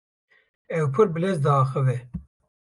Pronounced as (IPA) /bɪˈlɛz/